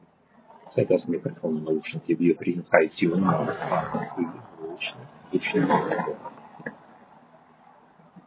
Мікрофон
Мікрофон в UGREEN HiTune Max5c непоганий, на 7 з 10, зроблений на достатньому рівні як по класу, так і в цілому.
В гучних умовах: